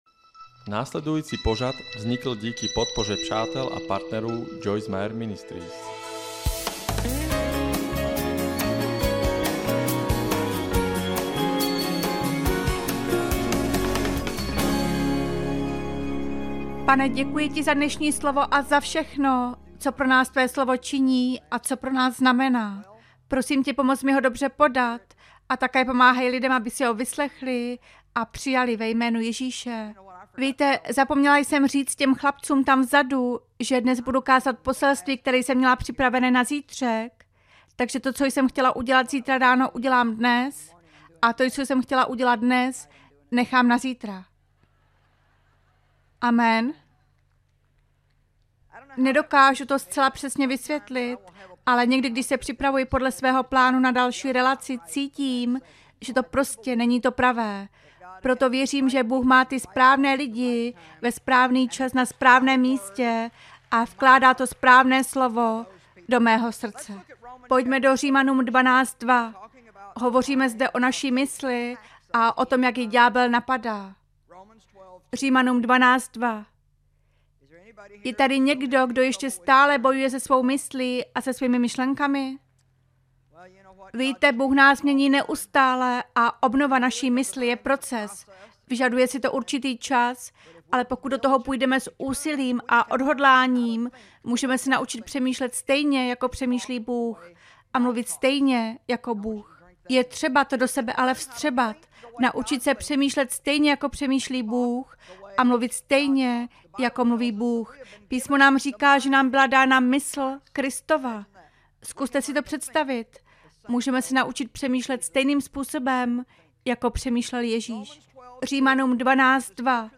Poslechněte si, jak autorka bestsellerů a učitelka Bible, Joyce Meyer, sdílí povzbuzení, které vám pomůže radovat se z každodenního života.